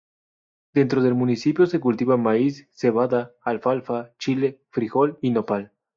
al‧fal‧fa
/alˈfalfa/